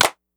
DrClap11.wav